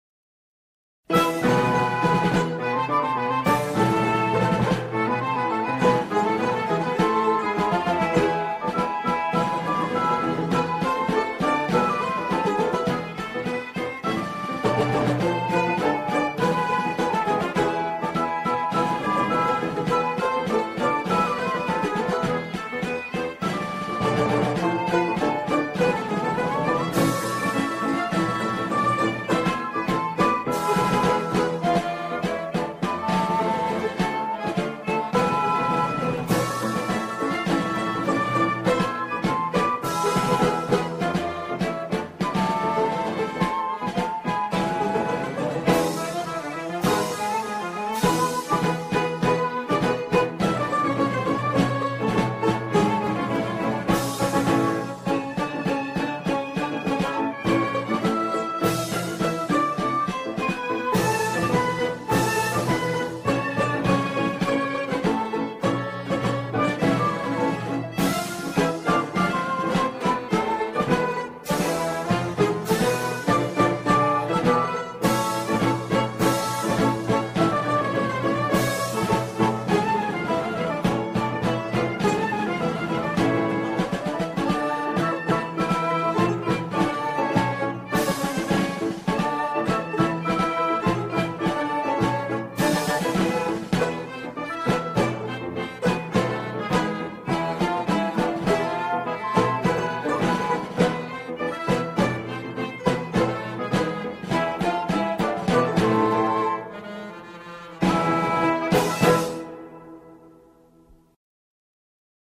سرودهای انگیزشی
بی‌کلام